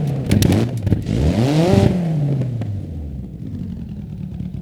Index of /server/sound/vehicles/lwcars/renault_alpine
slowdown_slow.wav